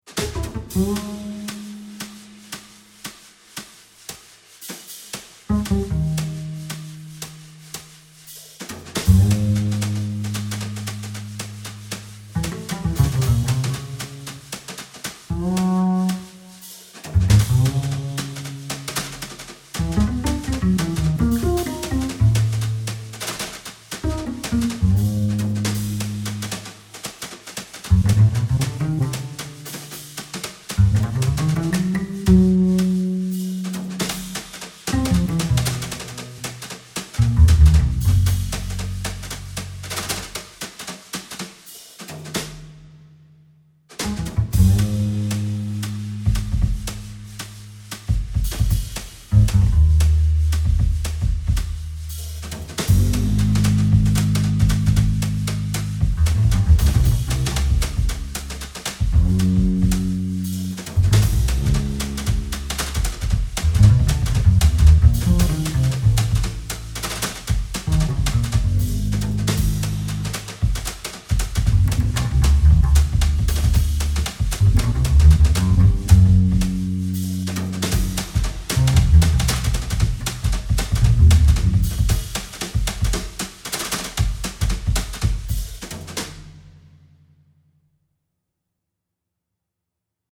drums and bass